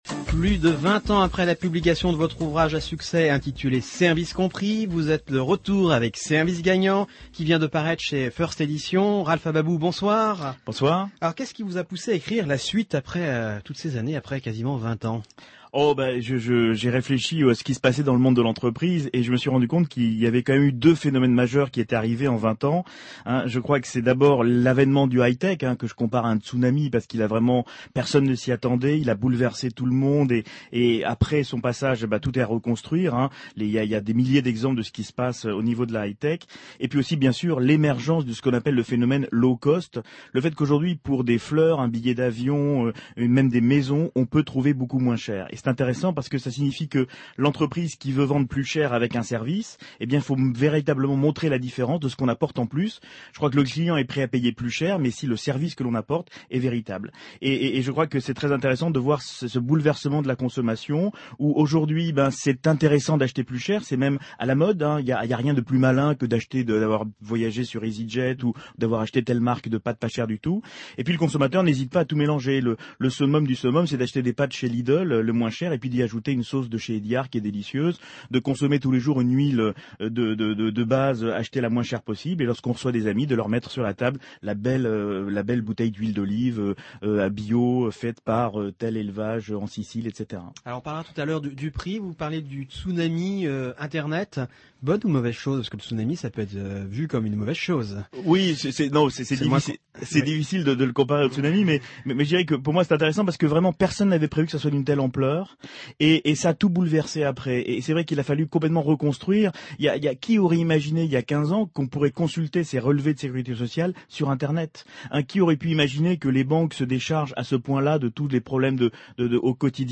interview-bfm.mp3